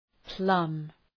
Προφορά
{plʌm}